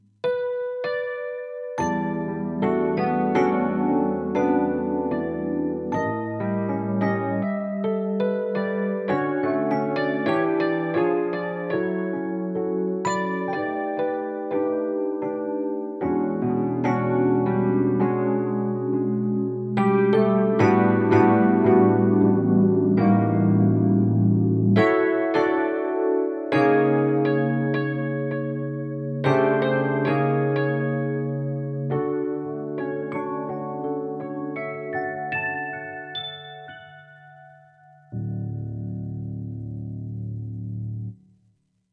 Rhodes_chr.mp3